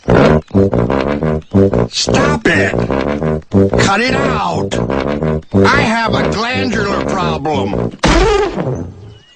stewie_tuba.mp3